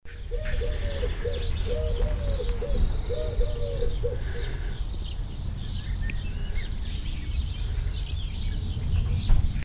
Paloma Torcaz (Columba palumbus)
Fase de la vida: Adulto
Localidad o área protegida: Cambridge
Condición: Silvestre
Certeza: Observada, Vocalización Grabada
Wood-Pigeon.MP3